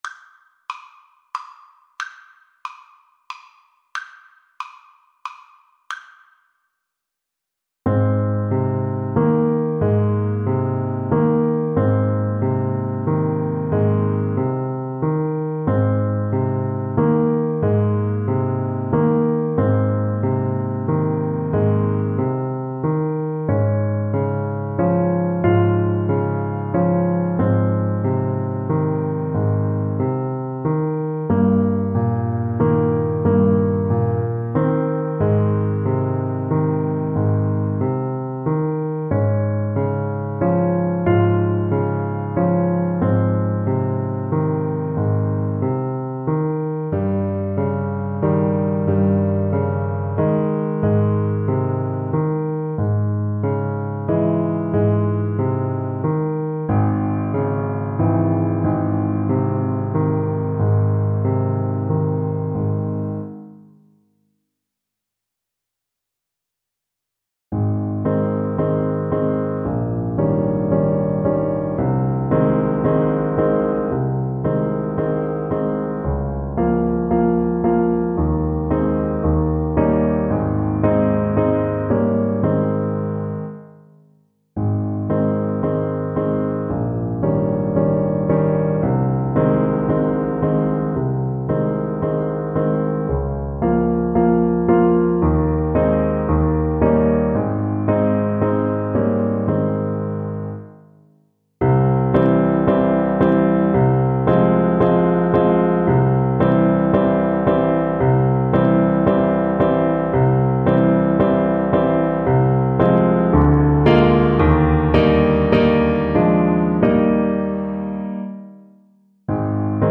Alto Saxophone
3/4 (View more 3/4 Music)
Andante sostenuto (.=48)
Classical (View more Classical Saxophone Music)